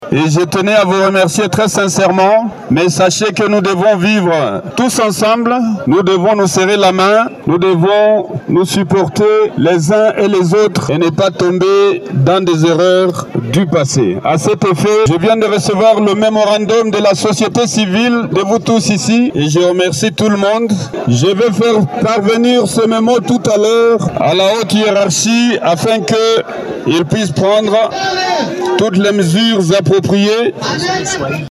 Répondant à la préoccupation des manifestants après réception du mémorandum, le gouverneur de province, le professeur Jean-Jacques Purusi a promis de le faire parvenir à sa haute hiérarchie pour des dispositions idoines.
ELEMENT-MANIFESTATION-SOCIV-BUKAVU-FR-2.mp3